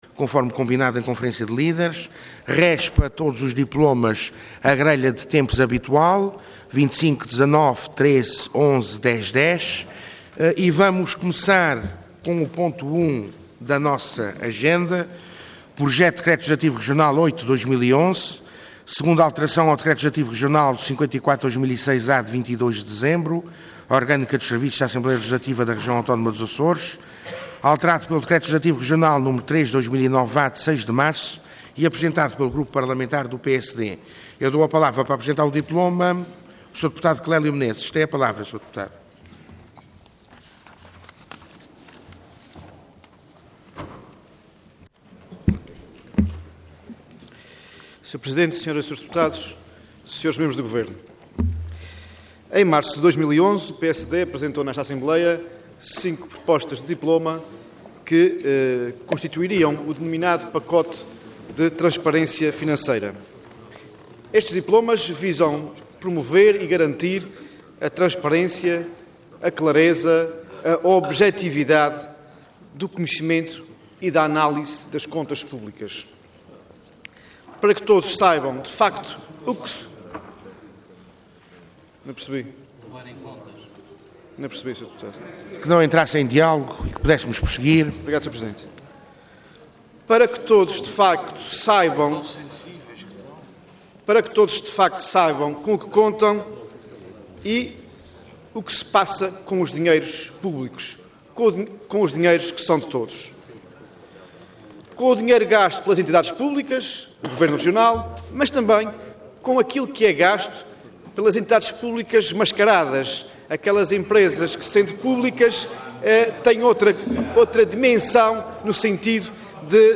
Intervenção Projeto de Decreto Leg. Orador Clélio Meneses Cargo Deputado Entidade PSD